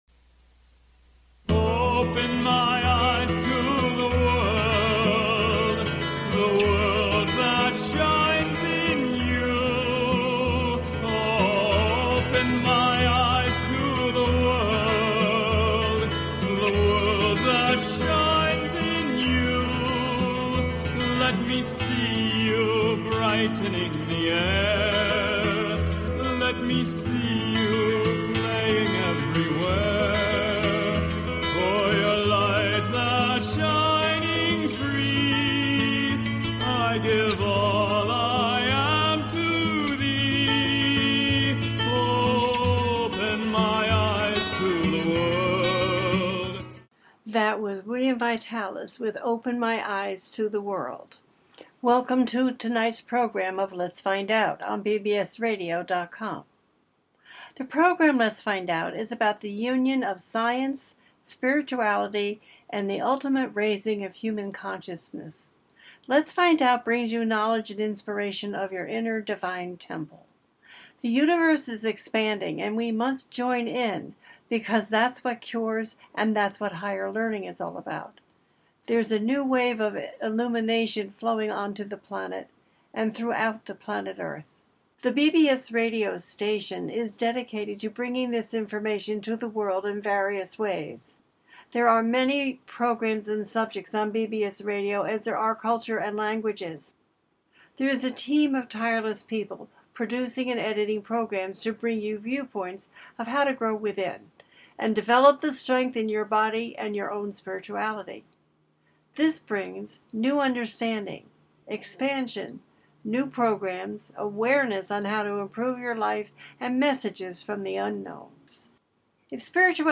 Talk Show
The listener can call in to ask a question on the air.
Each show ends with a guided meditation.